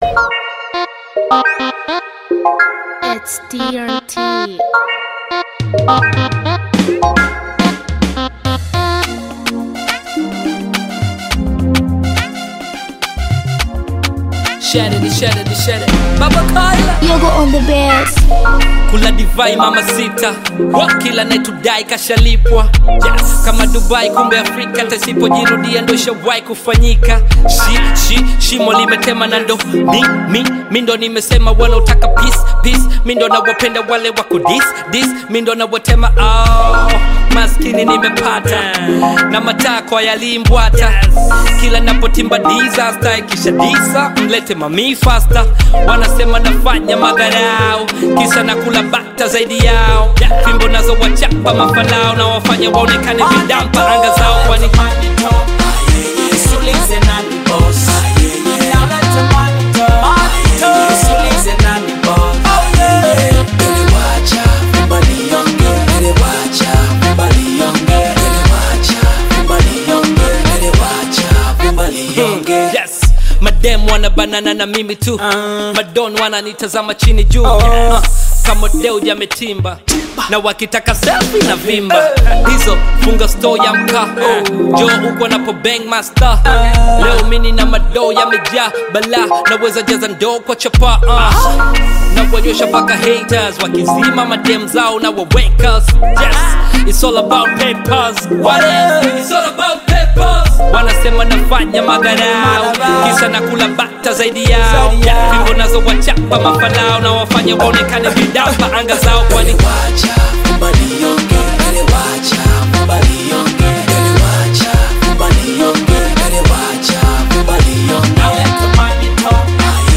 Bongo-Flava collaboration